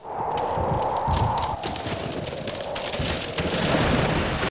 风雪声.mp3